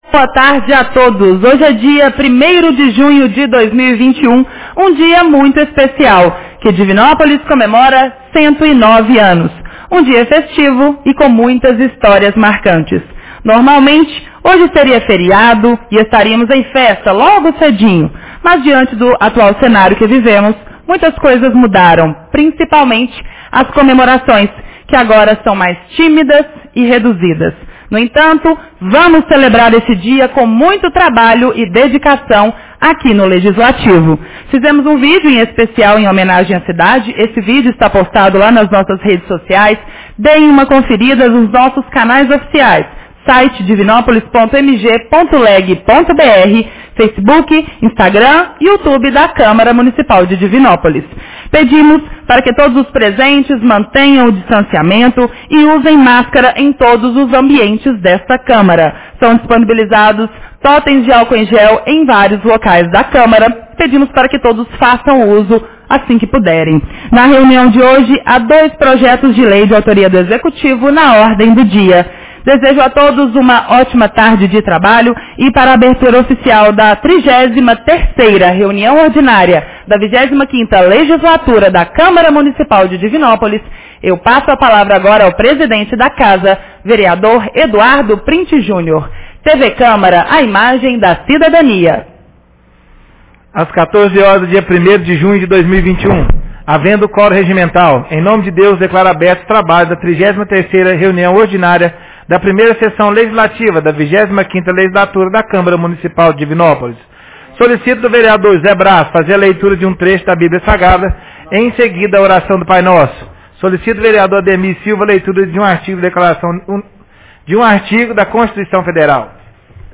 Reunião Ordinária 33 de 01 de junho 2021